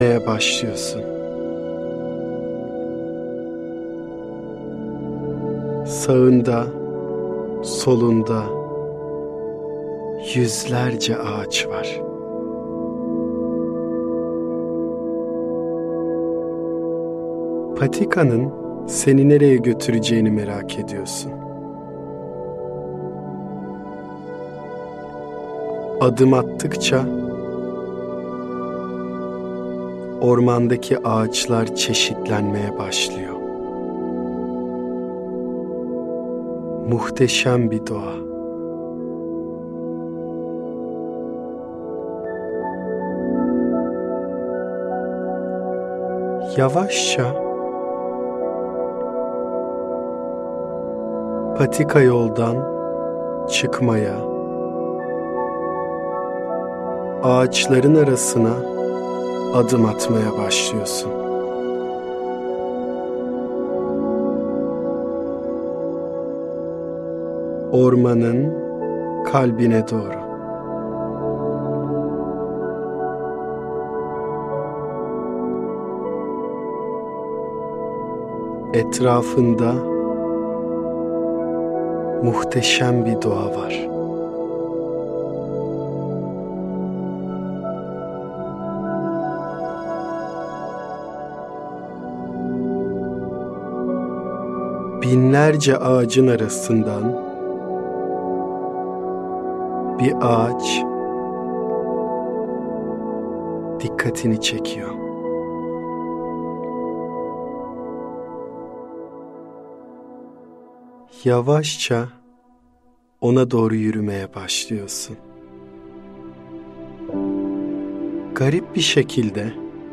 Meditasyon – Ağaçla Tanışma